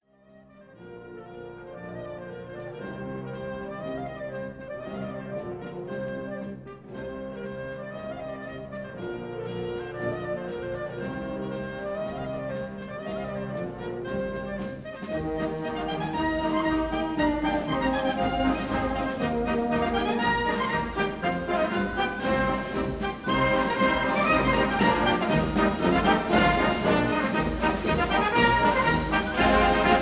recorded in summer 1955